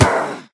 Minecraft Version Minecraft Version snapshot Latest Release | Latest Snapshot snapshot / assets / minecraft / sounds / entity / witch / death3.ogg Compare With Compare With Latest Release | Latest Snapshot
death3.ogg